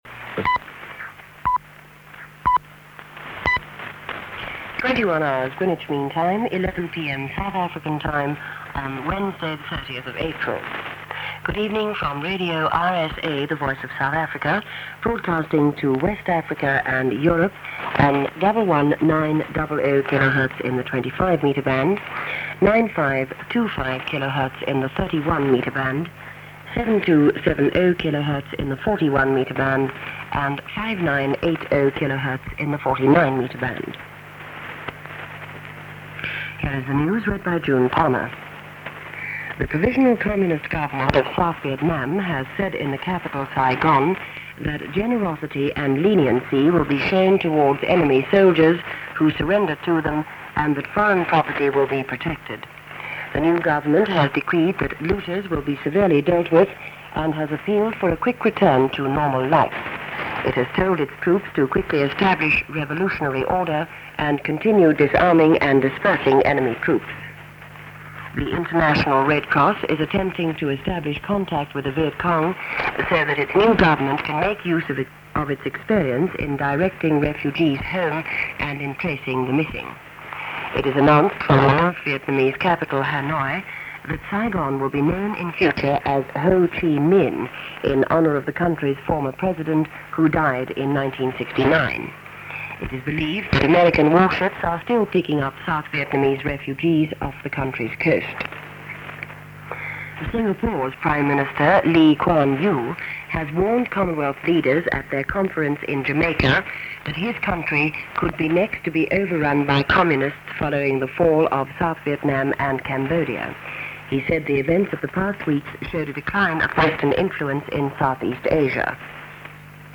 Shortwave coverage of the fall of Saigon as heard on Radio South Africa (RSA), Radio Nederland, Radio Japan and Radio Moscow on April 30, 1975.